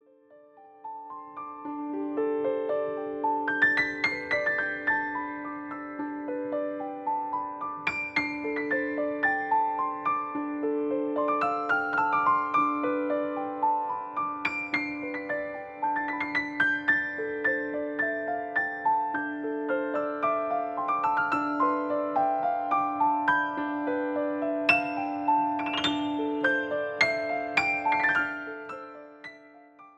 Piano Solo Version